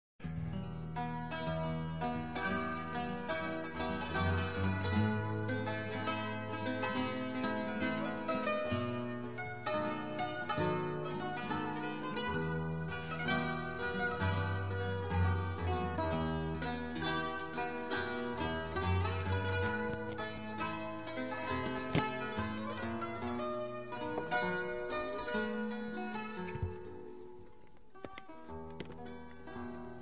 • canzone d'autore
• fado
• folklore elaborato
• registrazione sonora di musica